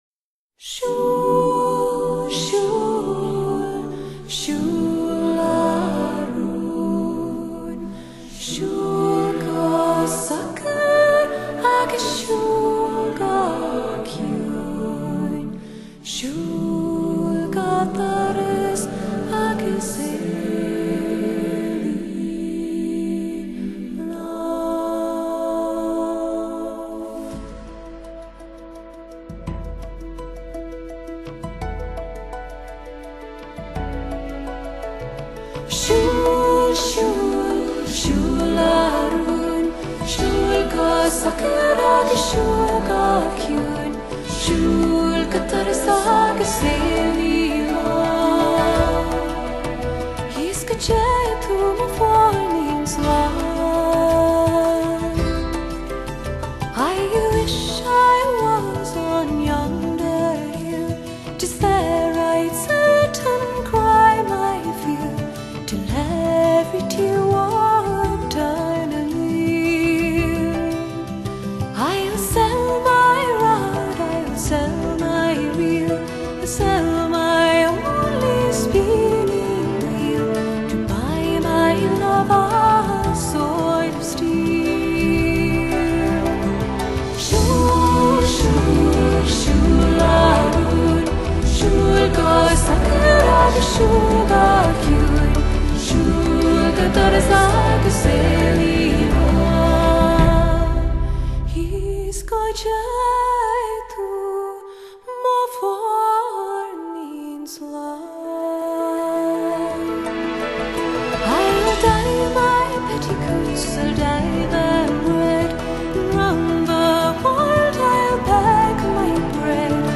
天籟美聲多如流水，但要找一個系列錄音極佳、音樂性豐富、活生感一流，讓你印象深刻的唱片卻很困難。